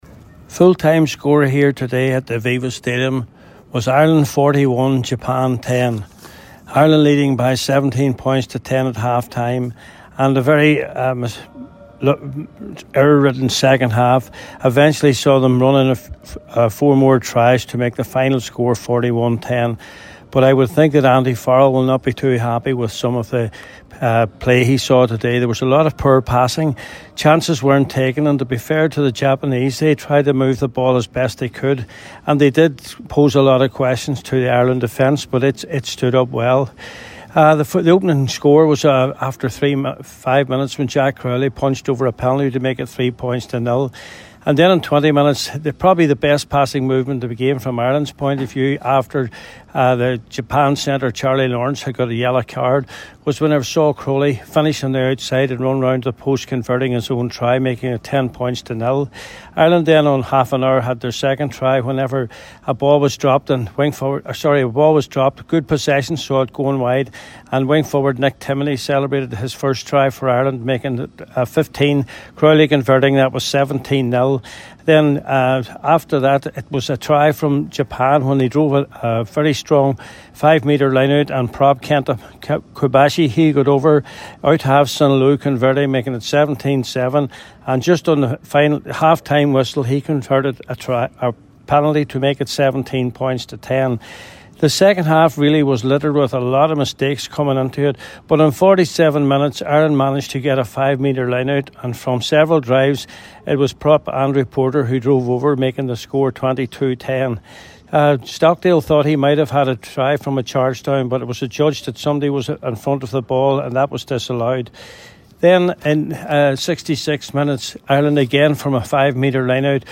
full time report from Dublin…